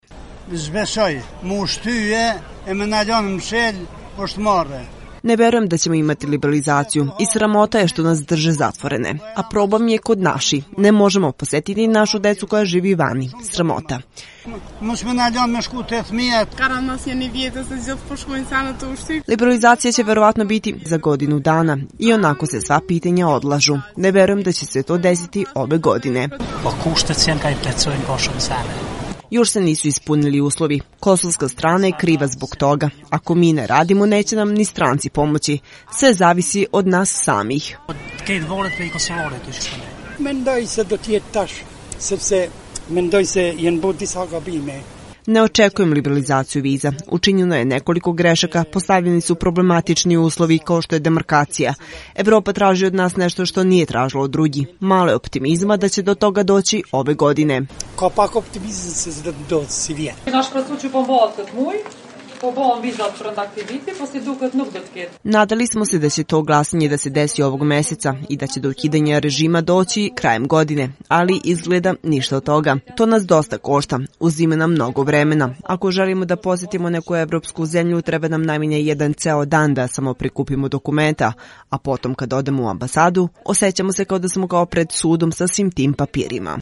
Anketa: Očekivanja građana